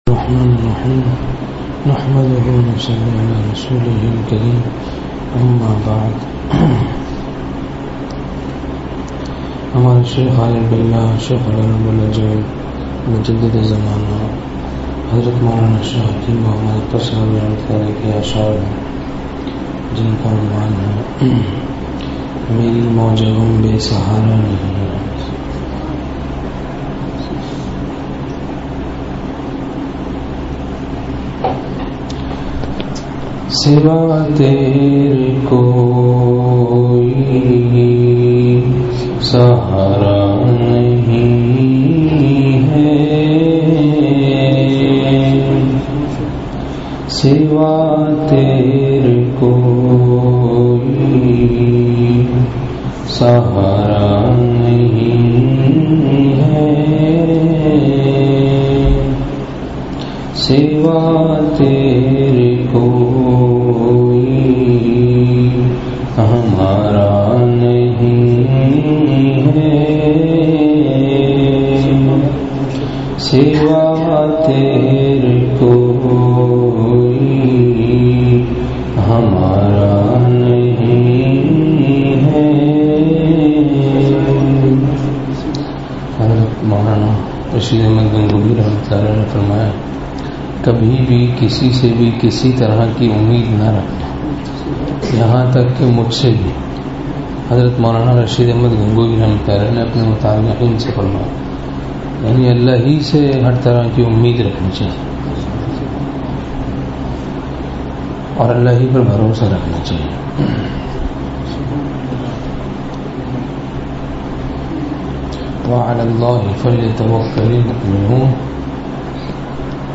جمعرات کو خانقاہ میں بیان